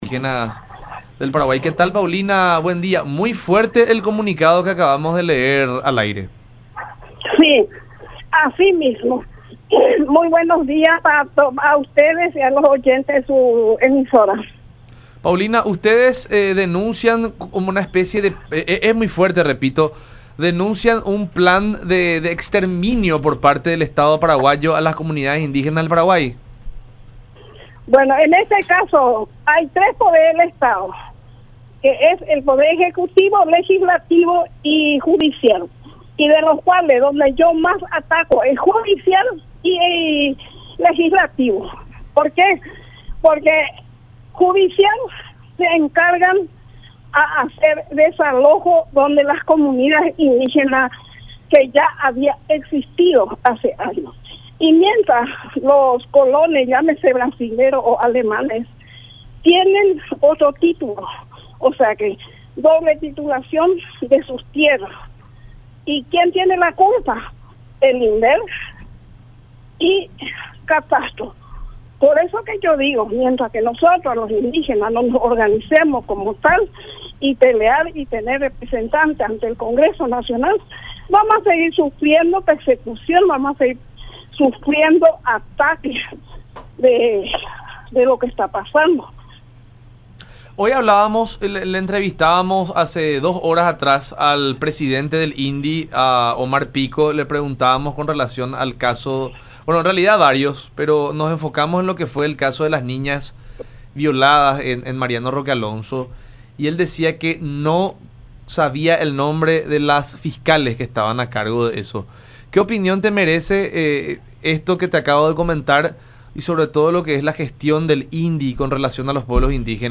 en charla con La Unión Hace La Fuerza por Unión TV y radio La Unión.